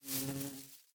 Minecraft Version Minecraft Version latest Latest Release | Latest Snapshot latest / assets / minecraft / sounds / mob / bee / pollinate4.ogg Compare With Compare With Latest Release | Latest Snapshot
pollinate4.ogg